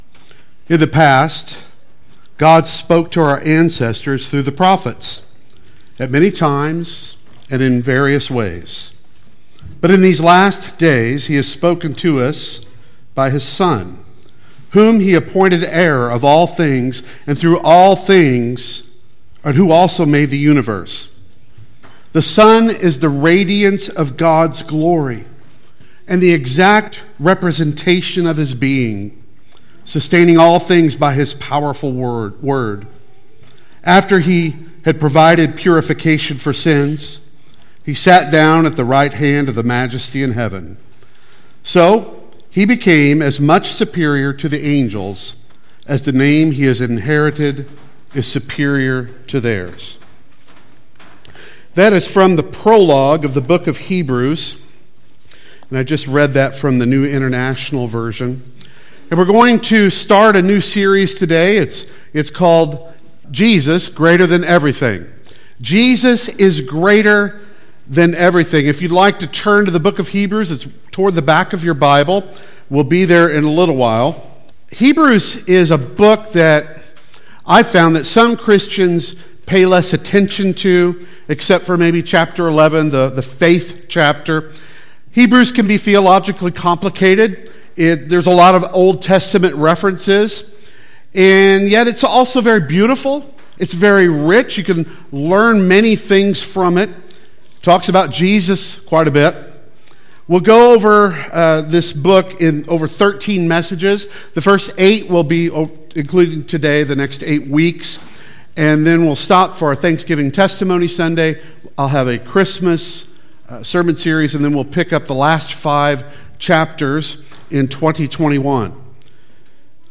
Service Type: Sunday Worship Service